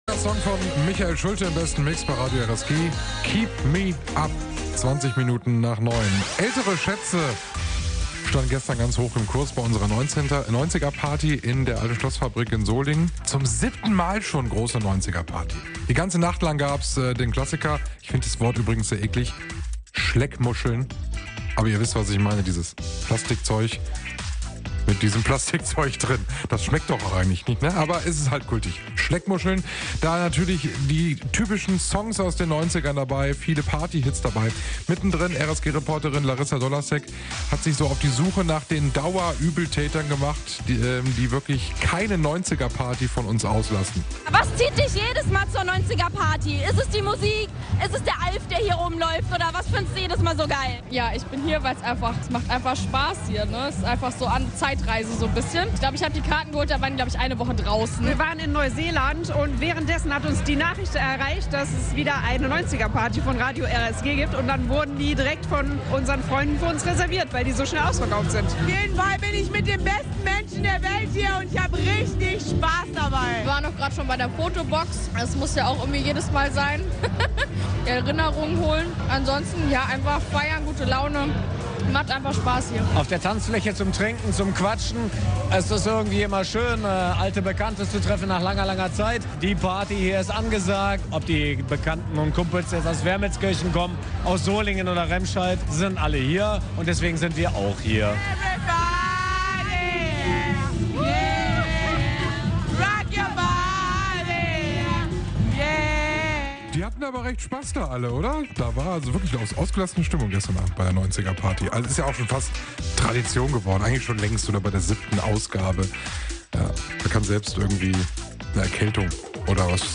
RSG-WochenendeDas war die 90er Party - Umfrage 1